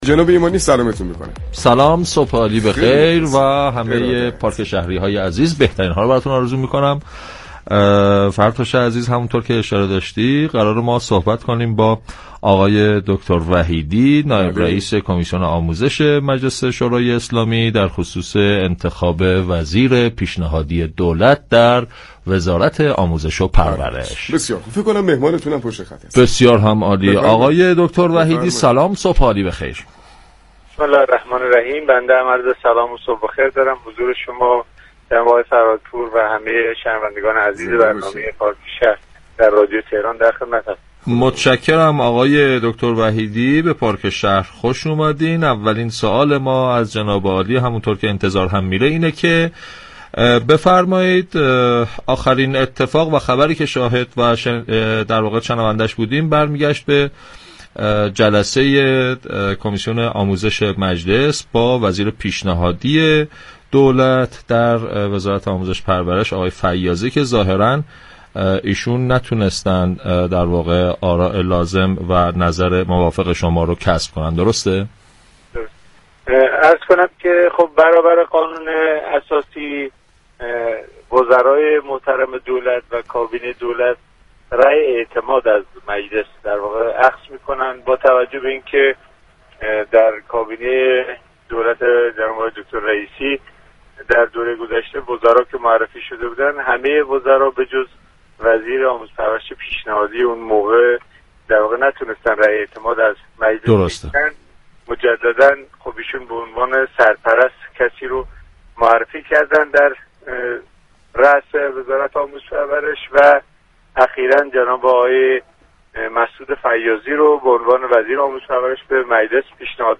به گزارش پایگاه اطلاع رسانی رادیو تهران، دكتر محمد وحیدی نایب‌رئیس كمیسیون آموزش و تحقیقات مجلس شورای اسلامی در گفتگو با پارك شهر رادیو تهران در خصوص رأی اعتماد مجلس به آقای فیاضی وزیر پیشنهادی آموزش‌وپرورش دولت سیزدهم گفت: طبق قانون اساسی وزرای پیشنهادی كابینه دولت از مجلس شورای اسلامی رأی اعتماد می گیرند.